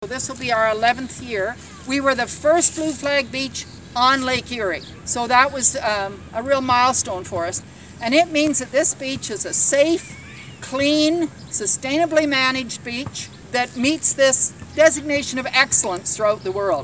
During a brief flag-raising ceremony this morning, Central Elgin Mayor Sally Martyn explained the significance of the world-renowned eco-certification.